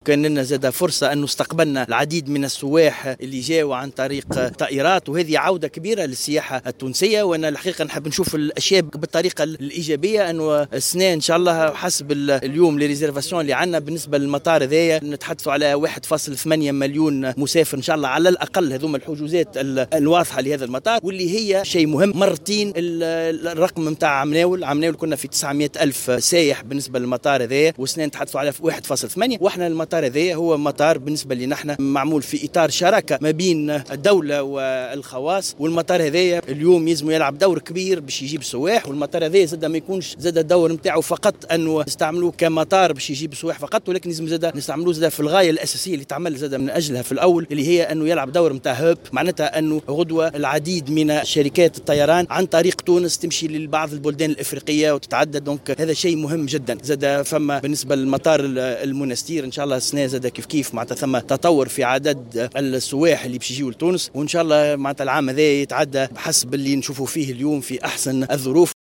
أكد وزير النقل هشام بن أحمد في تصريح للجوهرة "اف ام" على هامش زيارة له لمطار النفيضة اليوم الجمعة ارتفاع عدد السياح الوافدين على تونس عبر المطار المذكور.